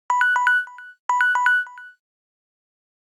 08_Ticktac.ogg